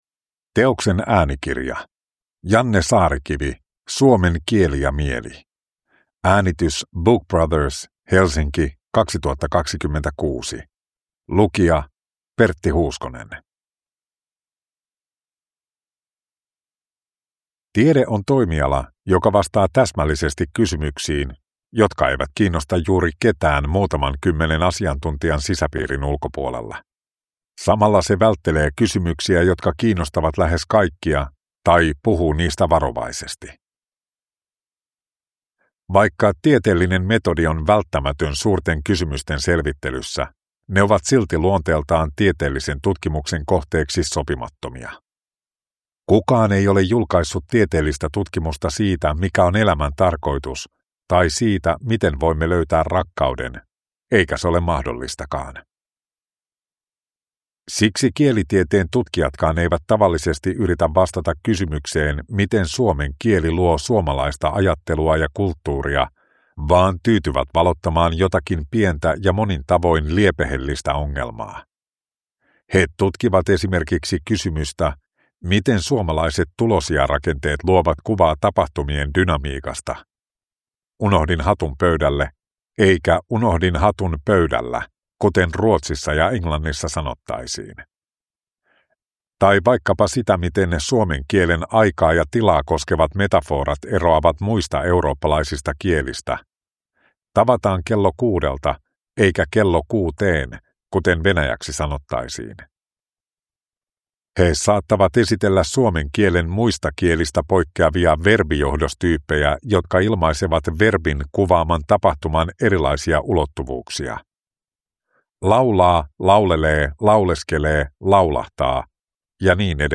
Suomen kieli ja mieli – Ljudbok